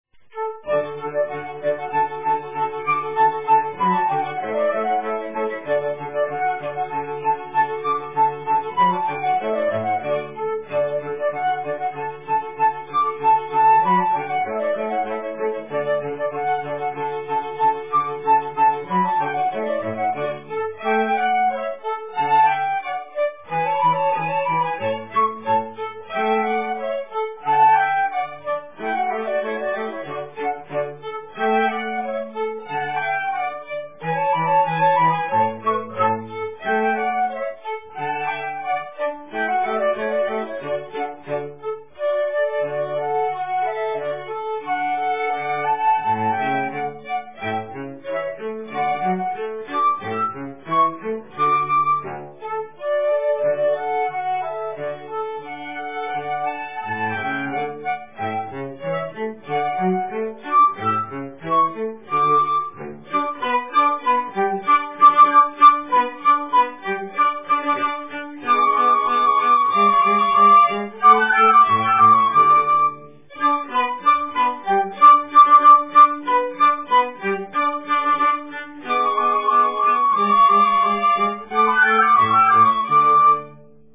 Country Dance #3